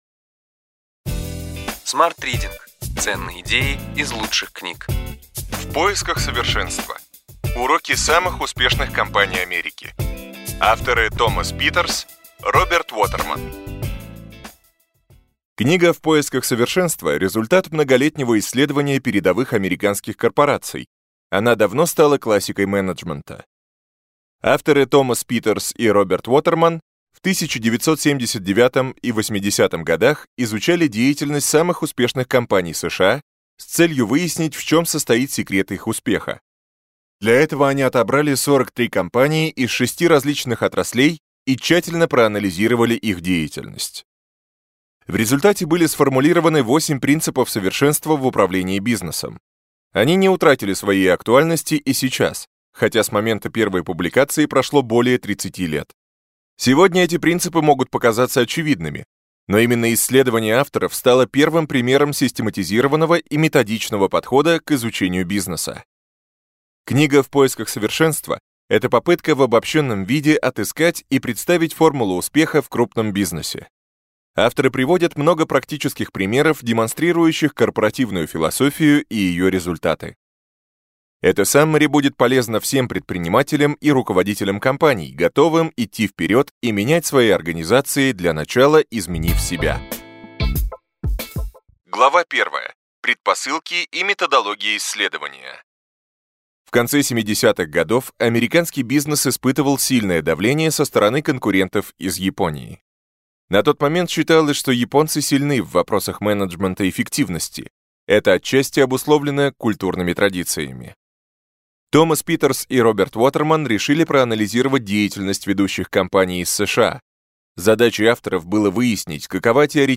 Аудиокнига Ключевые идеи книги: В поисках совершенства. Уроки самых успешных компаний Америки.